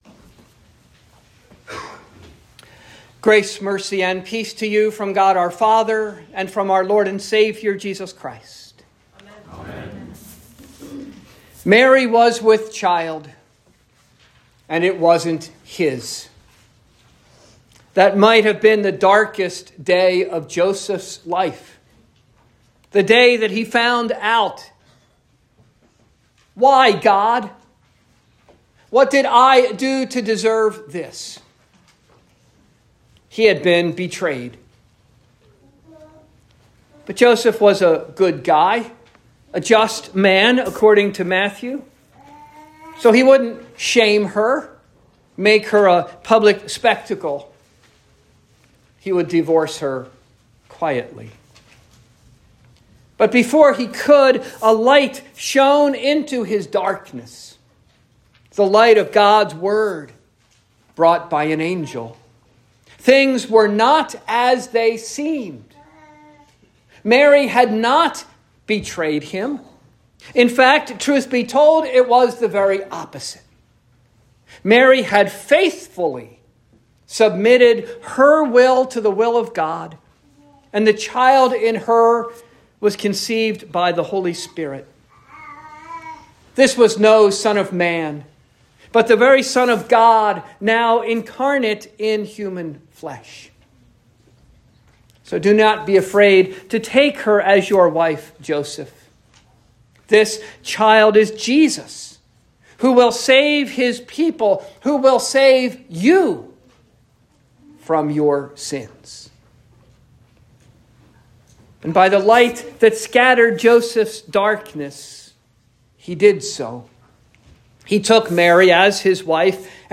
Saint Athanasius Lutheran Church Advent Sermons 2022-